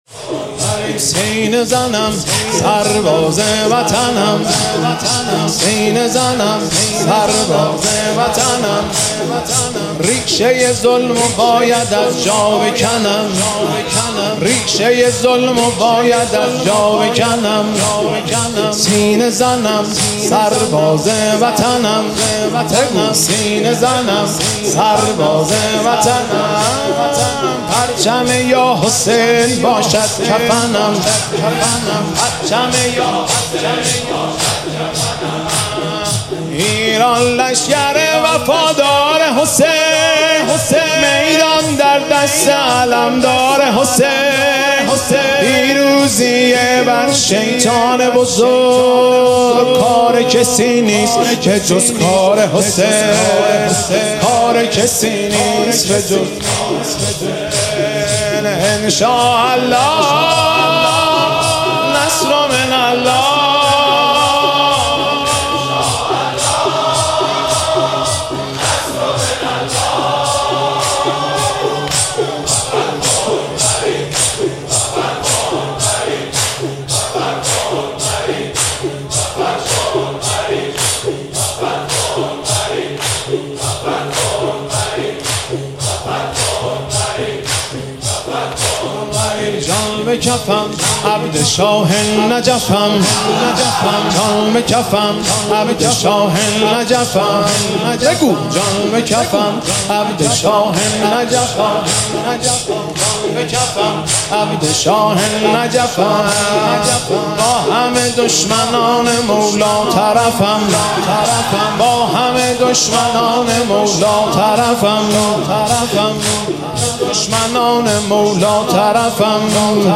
مداحی سینه زنم سرباز وطنم/ مهدی رسولی
به گزارش شهدای ایران: سینه زنم سرباز وطنم/ پشت به دشمن بکنم بی‌شرفم - مداحی حاج مهدی رسولی در هیات اصحاب الحسین(ع) دانشگاه تهران